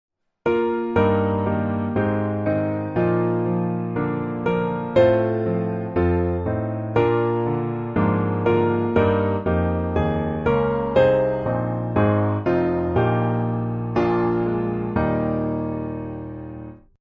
Gospel
Eb Major